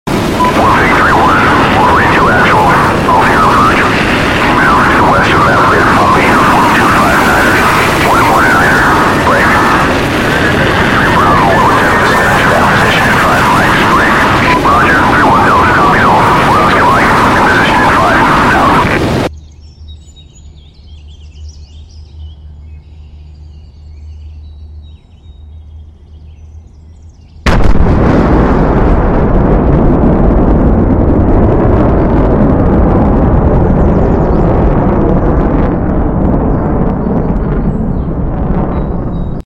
F-14B goes supersonic over Nevada.